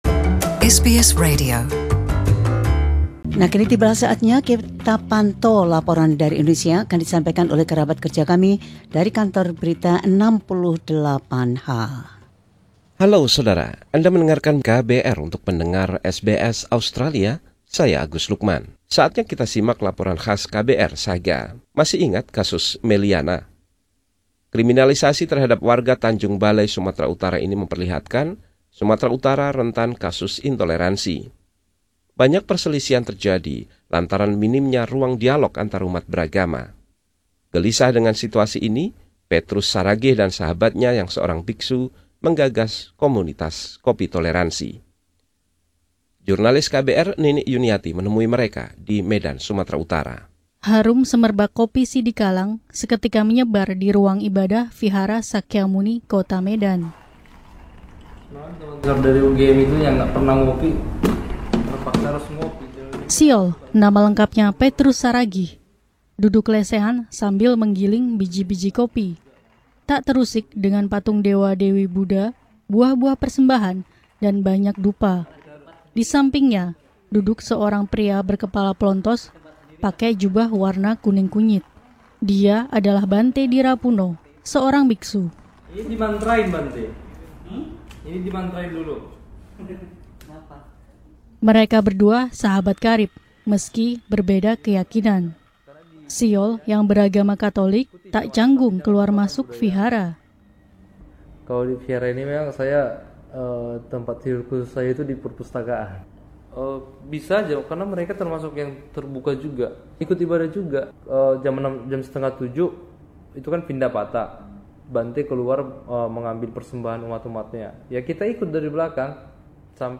Laporan khusus dari tim KBR 68H ini mengisahkan tentang komitmen terhadap keberagaman.